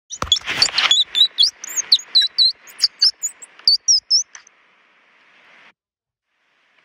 Whistling
These high-pitched, melodious calls are used in various contexts, from expressing alarm to attracting mates.
Whistling.mp3